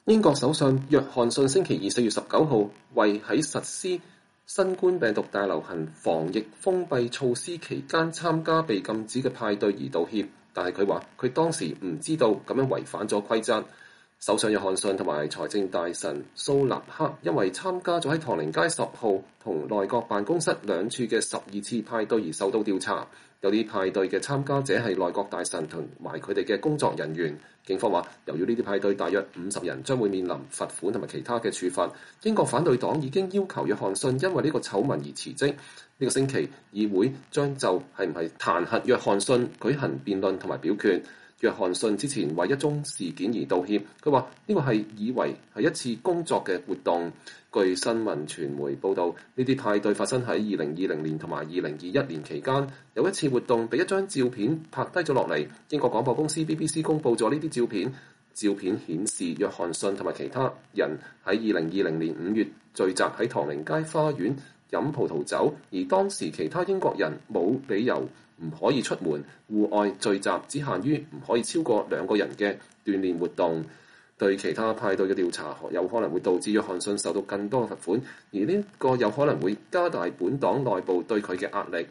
英國首相約翰遜在下議院就有關唐寧街據稱在實行新冠疫情封閉措施期間舉辦派對的一份報告發布聲明。